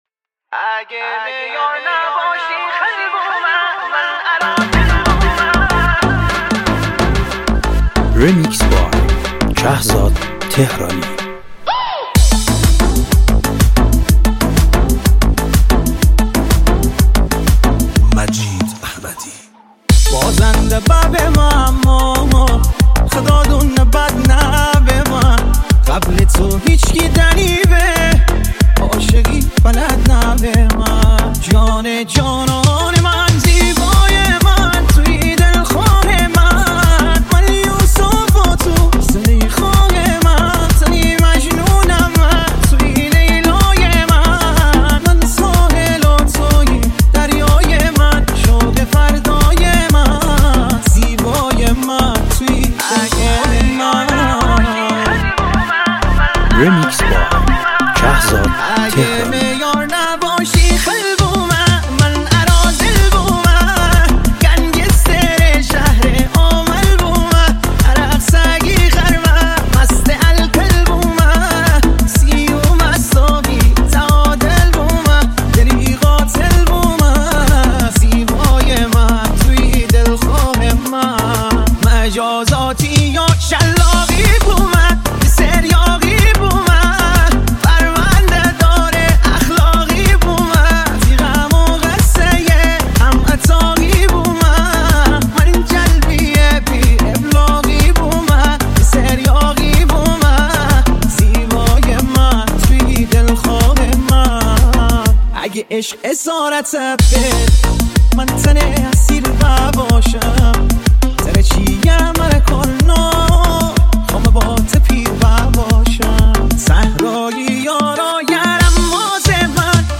ریمیکس 320